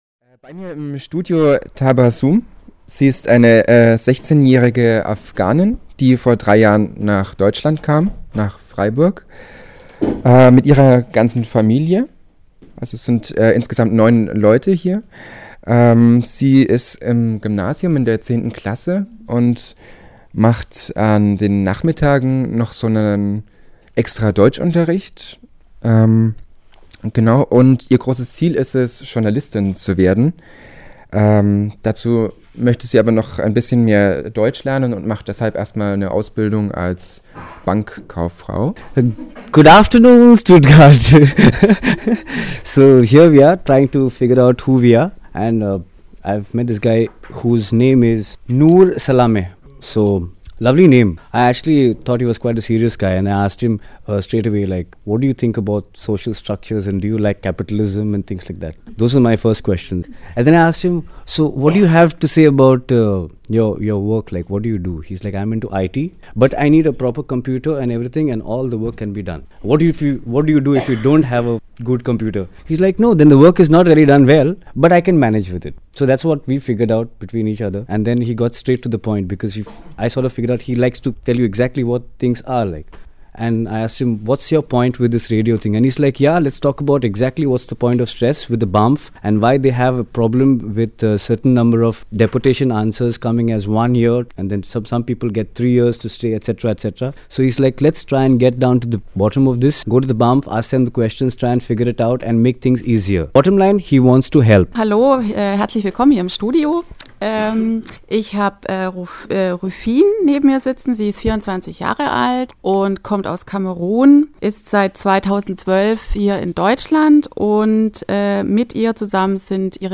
In Februar trafen sich die Flüchtlingsredaktion aus verschiedenen freien Radios zum Austauschen und für einen Workshop in Stuttgart.
Ich habe zwei Beiträge gamacht, im dem ersten Beitrag haben die Leute sich vorgestellt.
62903_Vorstellungsrunde_inidividuell_fertig.mp3